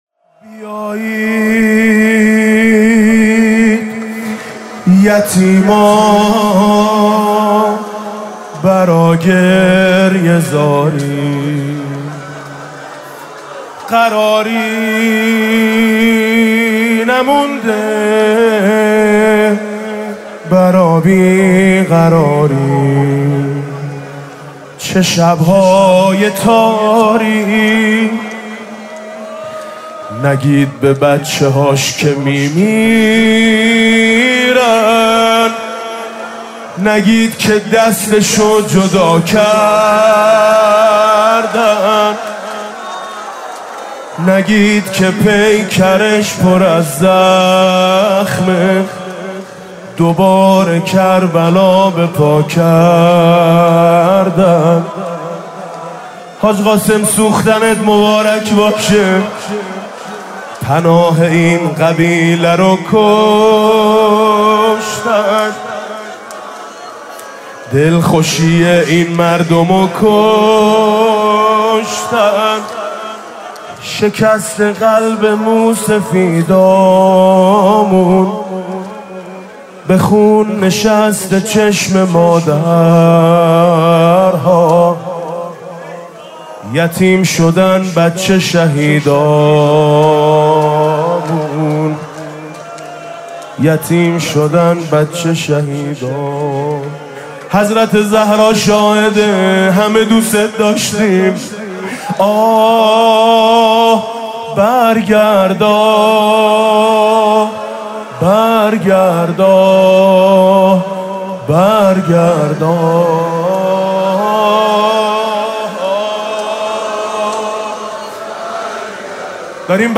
فاطمیه اول 98 - شب اول - زمزمه - خدا ازت قبول کنه سردار